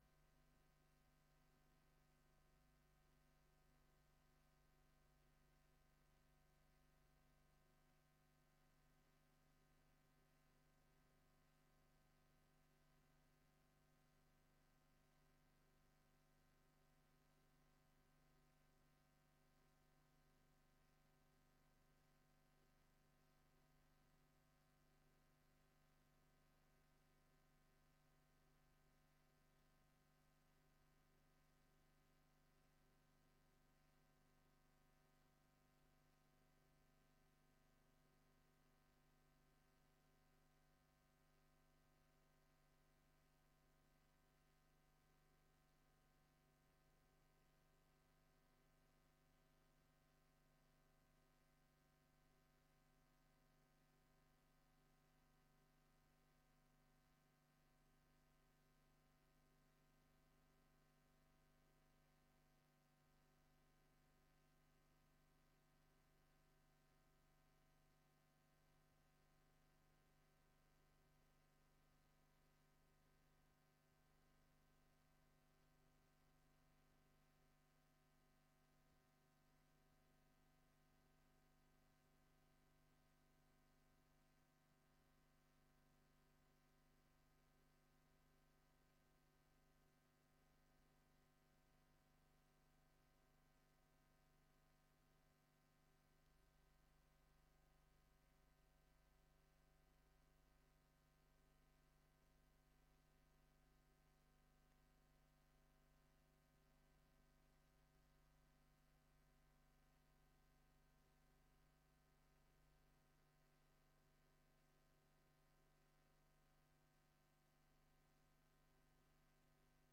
Gemeenteraad 13 september 2022 20:00:00, Gemeente Tynaarlo
Download de volledige audio van deze vergadering
Locatie: Raadszaal